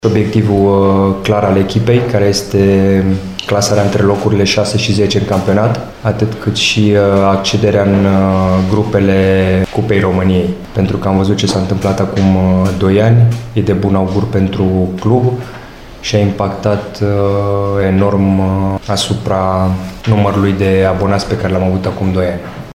Directorul sportiv, Claudiu Keseru, a precizat astăzi obiectivele pentru sezonul ce urmează: